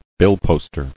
[bill·post·er]